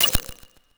s013_GachaOpen.wav